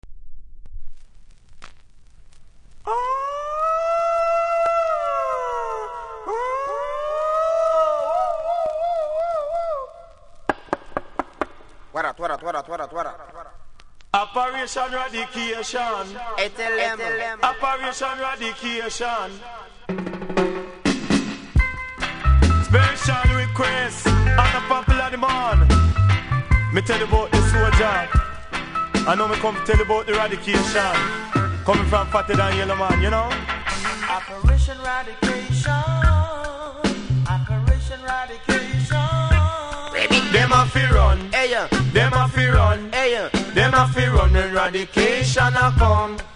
REGGAE 80'S
多少うすキズありますが音は良好なので試聴で確認下さい。